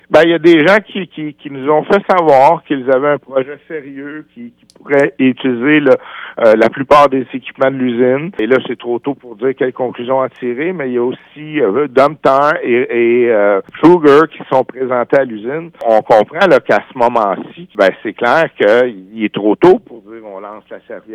En entrevue sur nos ondes lundi matin